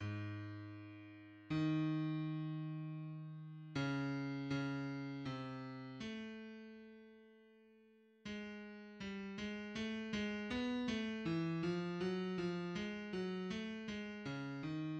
No. 7 C minor